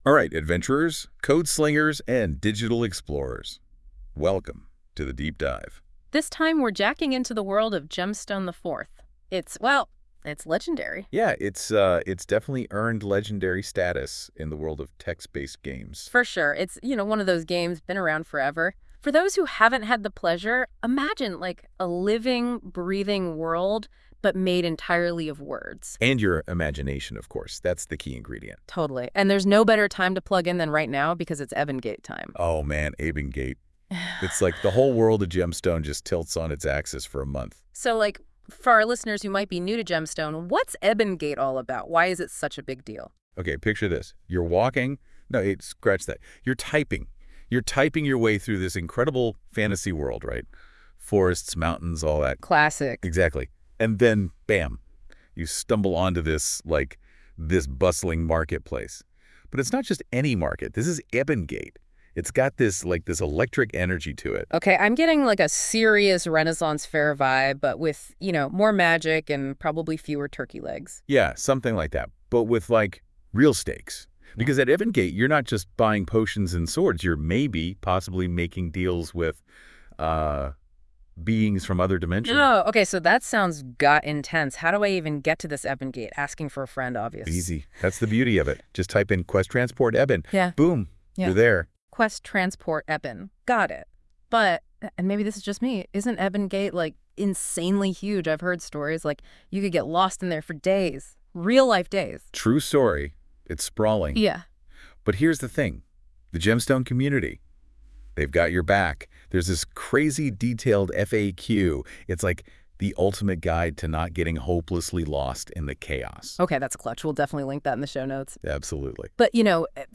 This was a test of Google NotebookLM. We gave it an announcement, referenced this web site, and NotebookLM created this incredible, nearly perfect Podcast.